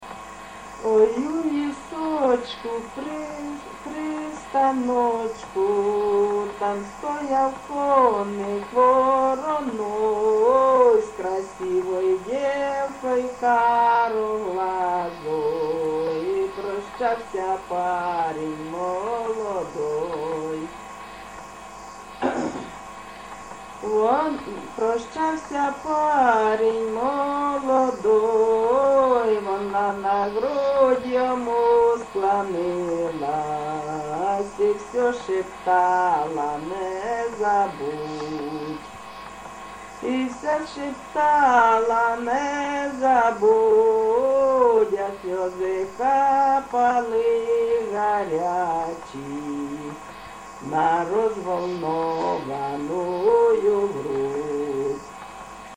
ЖанрСучасні пісні та новотвори
Місце записус. Олександро-Калинове, Костянтинівський (Краматорський) район, Донецька обл., Україна, Слобожанщина